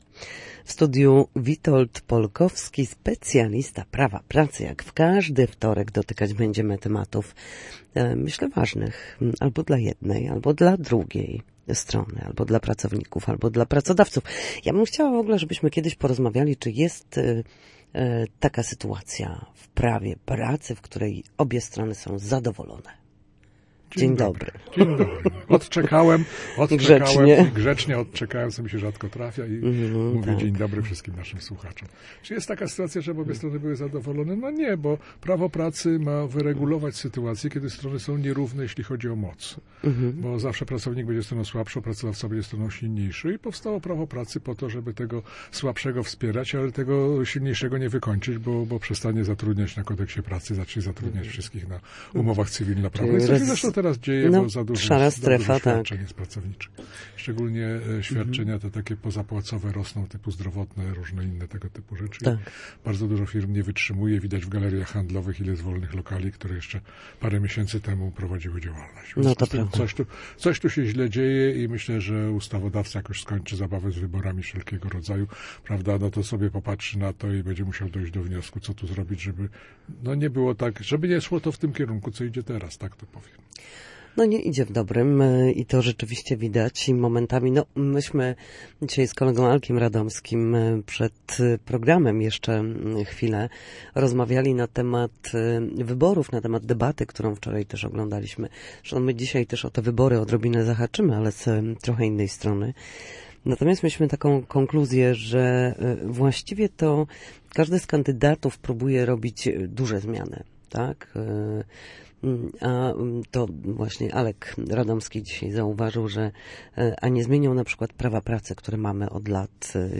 W każdy wtorek po godzinie 13:00 na antenie Studia Słupsk przybliżamy zagadnienia dotyczące prawa pracy.